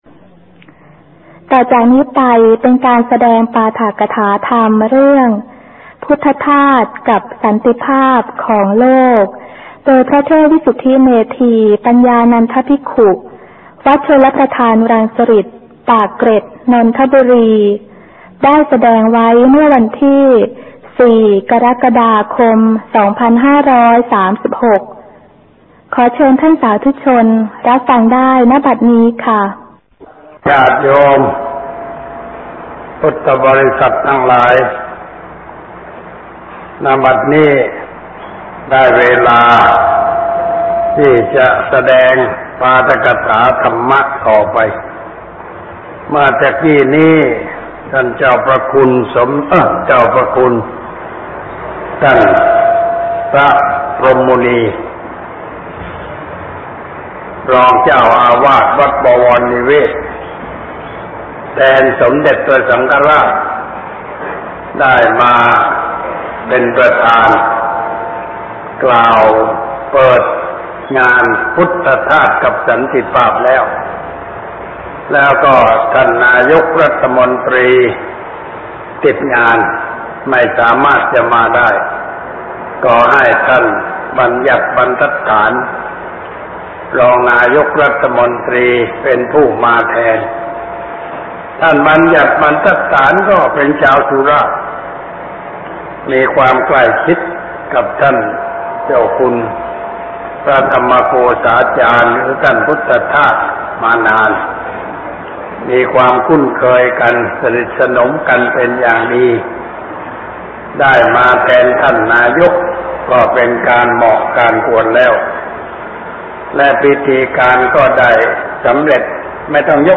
ต่อจากนี้ไป เป็นการแสดงปาฐกถาธรรมเรื่อง “พุทธทาสกับสันติภาพของโลก” โดยพระเทพวิสุทธิเมธีปัญญานันทภิกขุ วัดชลประทานรังสฤษฎิ์ ปากเกร็ด นนทบุรี ได้แสดงไว้เมื่อวันท ...